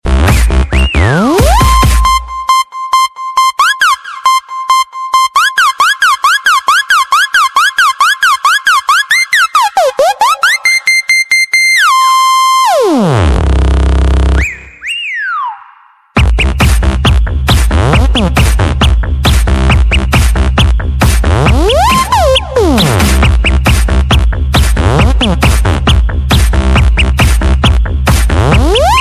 Kategorien Soundeffekte